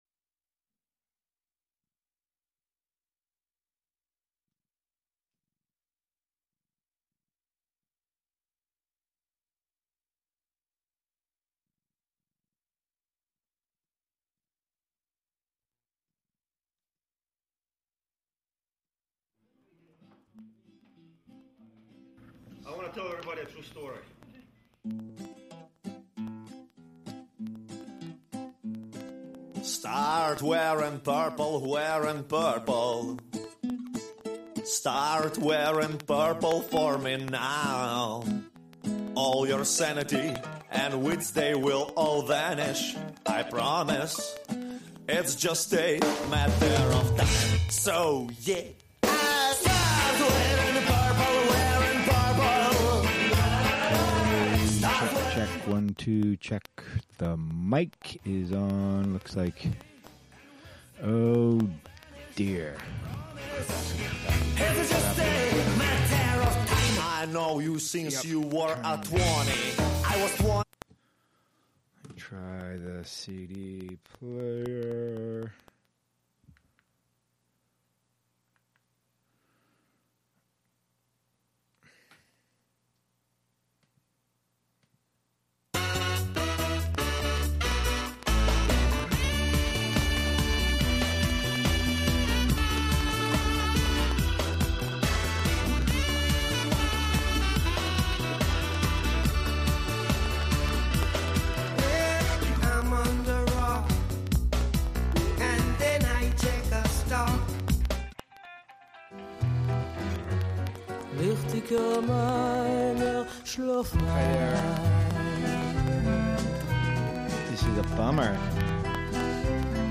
Prime Jive: Monday Afternoon Show- Live from Housatonic, MA (Audio)
broadcasts live with music, call-ins, news, announcements, and interviews